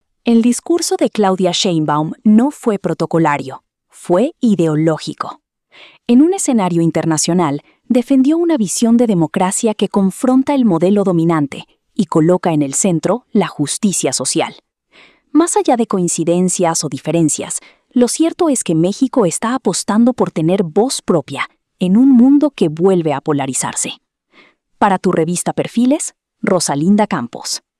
🎙 COMENTARIO EDITORIAL